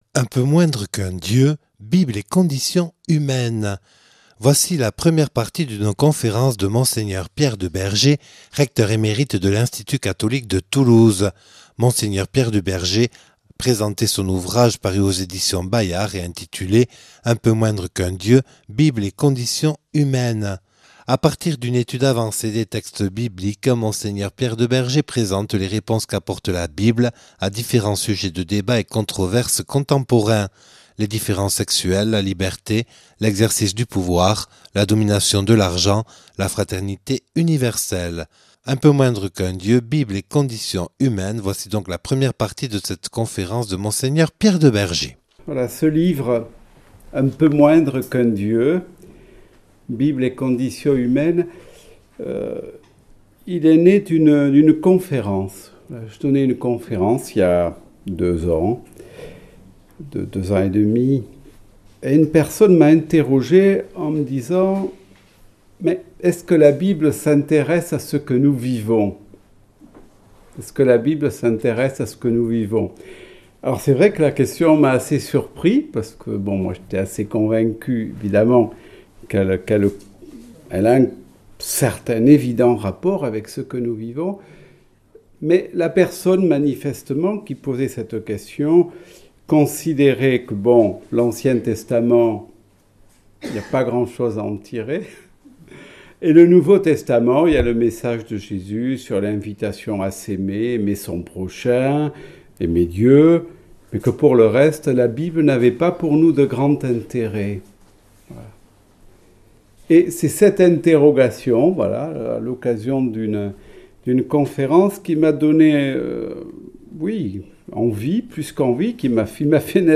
Conférence
(Enregistré le 12/04/2014 à l’abbaye Notre Dame de Belloc à Urt).